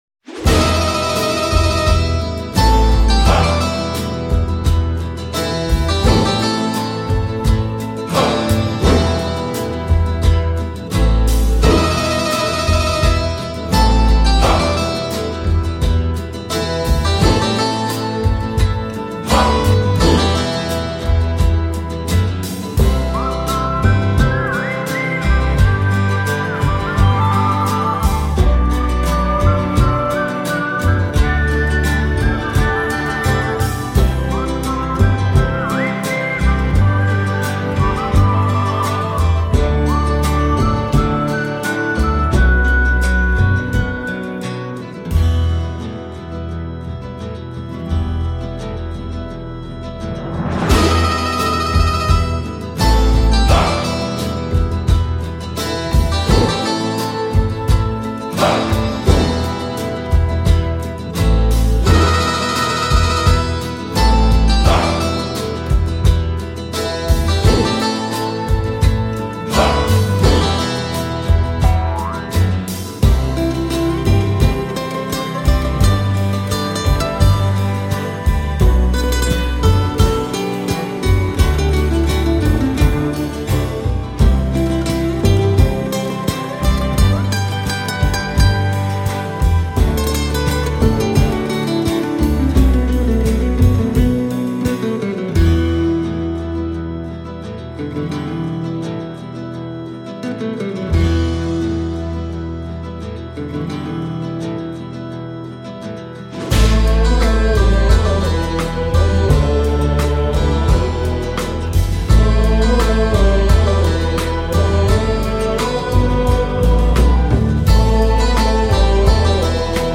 C’est un hommage clair aux B.O. des années 70